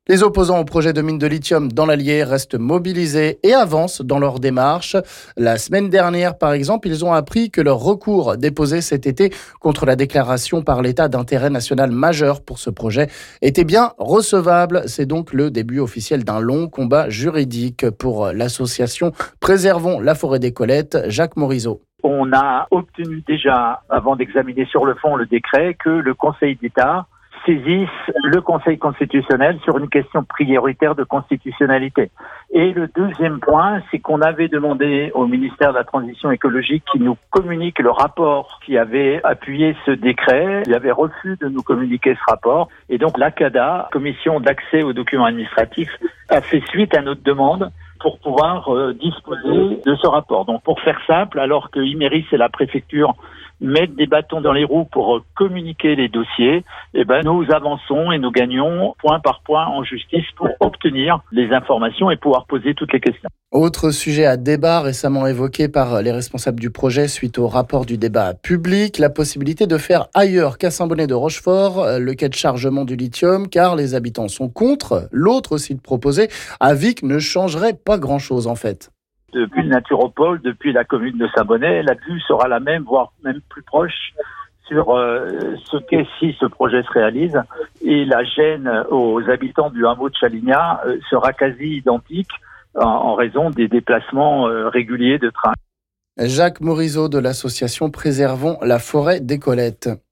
On écoute ici l'un des responsables de l'association "Préservons la forêt des Colettes"...